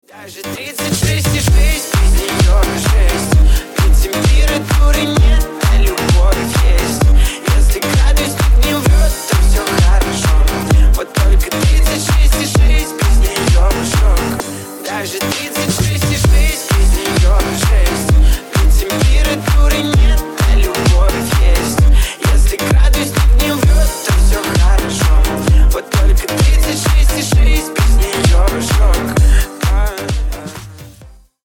поп , ремиксы